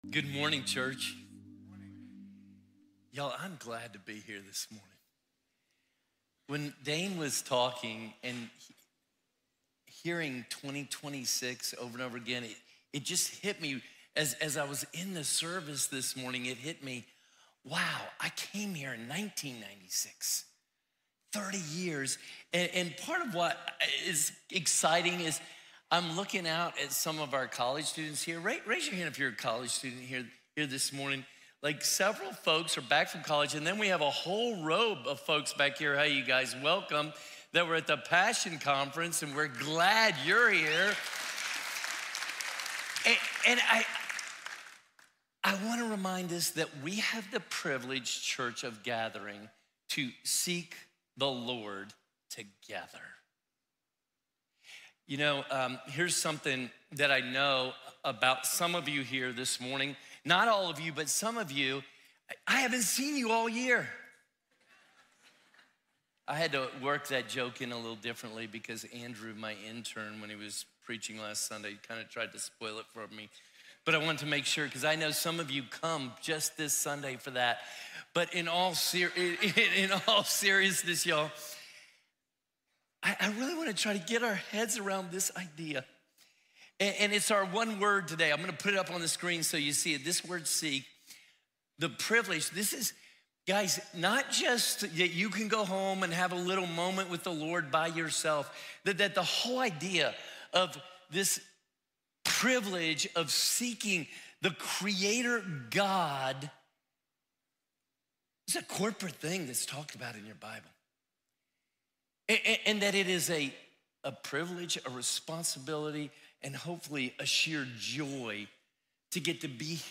Prayer+Service+Start+With+Seeking+++Northwest+Bible+Church.mp3